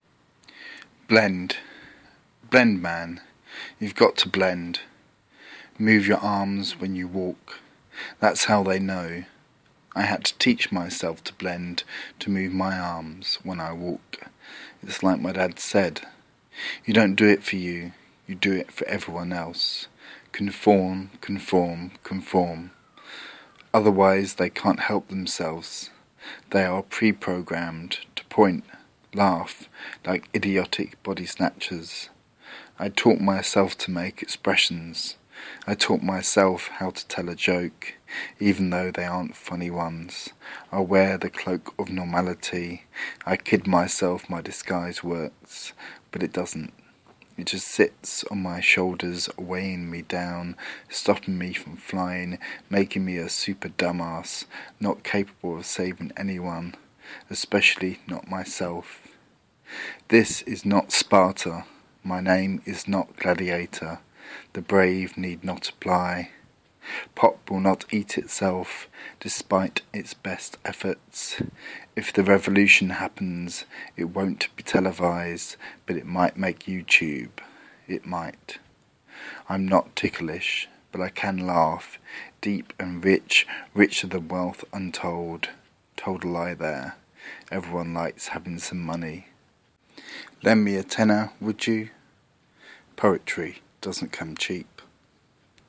Spoken word